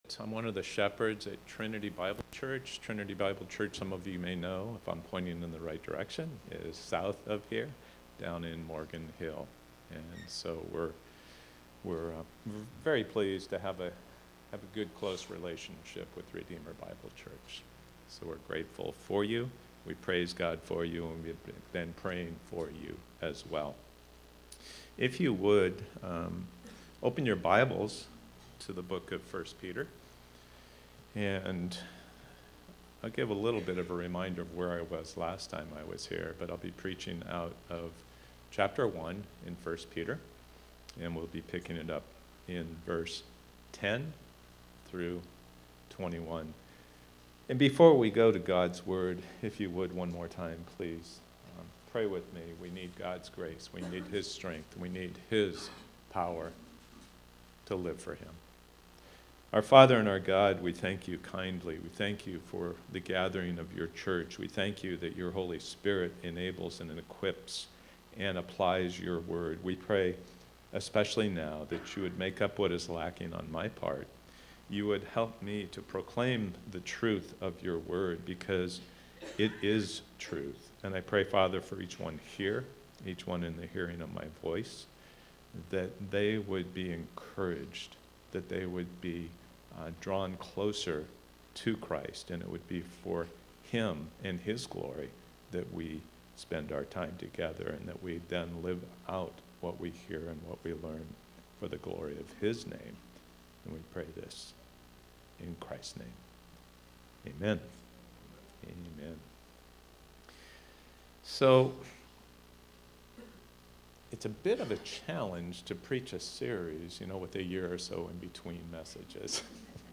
Guest Speaker | Saved To Be Holy | Redeemer Bible Church